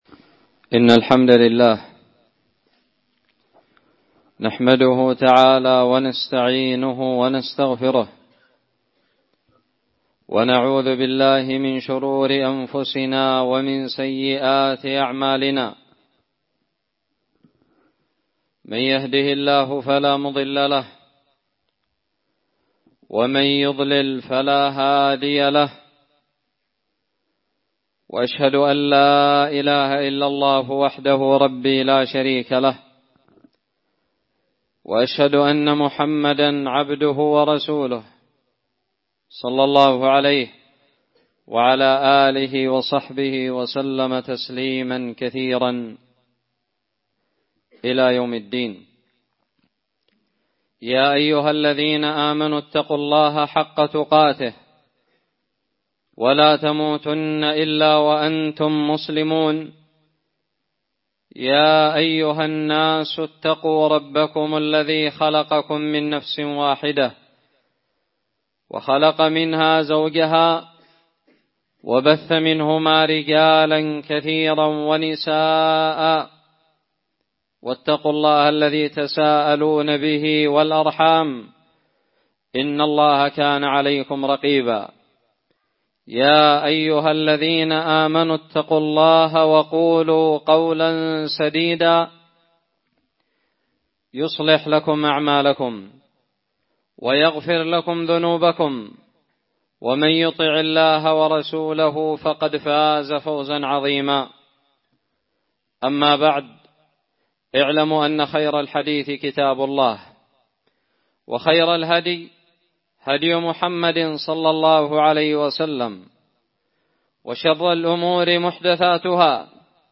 خطب الجمعة
ألقيت بدار الحديث السلفية للعلوم الشرعية بالضالع في 21 جمادى الآخرة 1439هــ